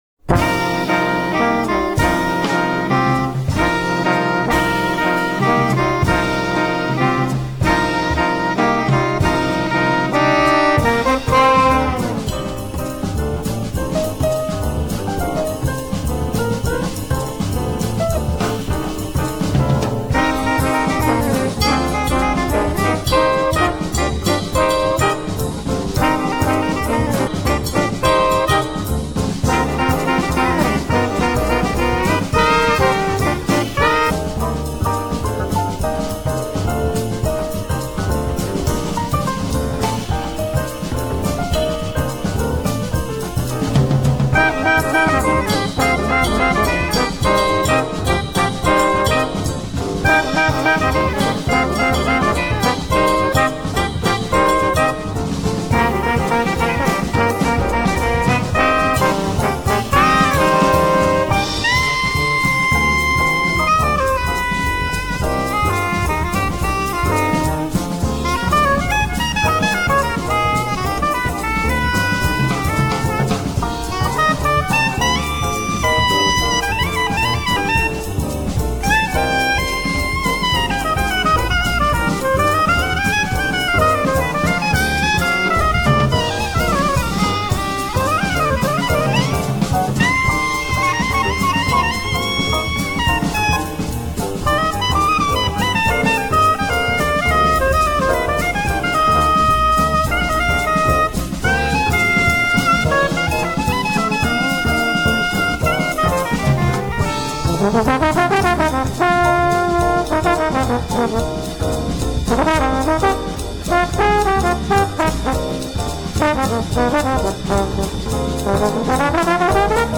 three-horn arrangements
saxophones
piano
bass
drums
flute